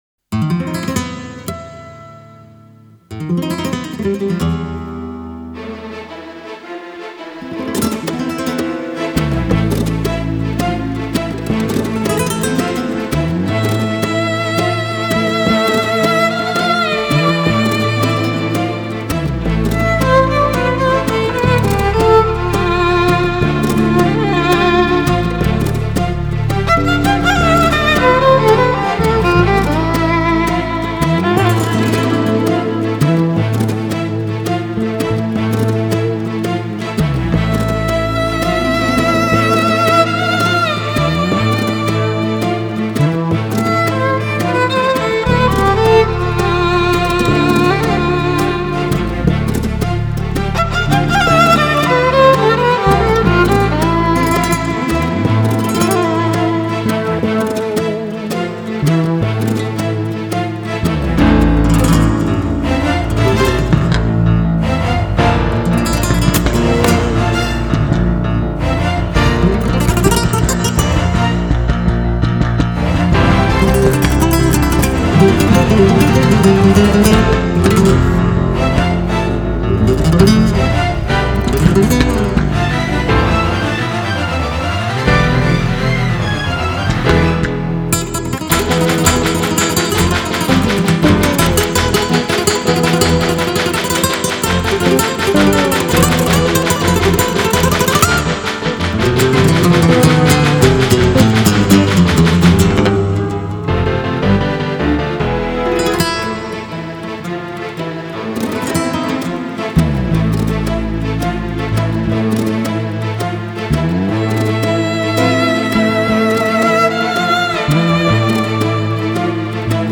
장르: Electronic, Jazz, Pop
스타일: Modern Classical, Easy Listening